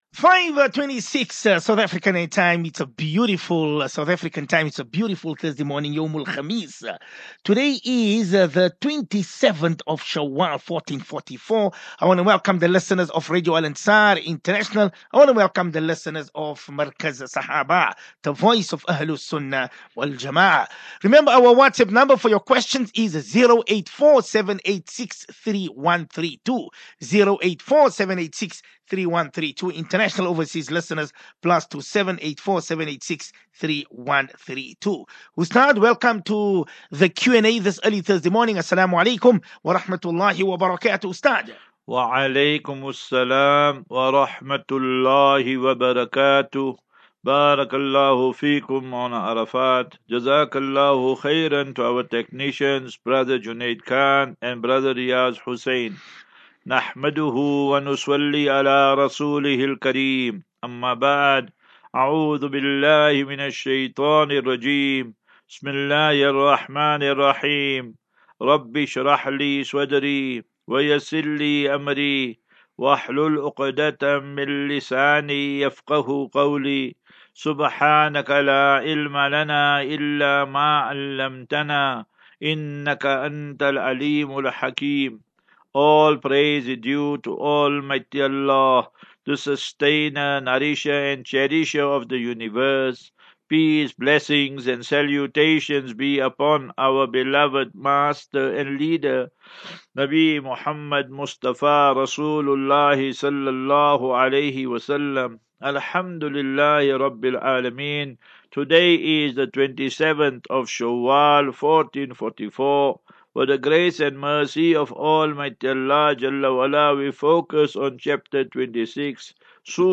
Daily Naseeha.
As Safinatu Ilal Jannah Naseeha and Q and A 18 May 18 May 23 Assafinatu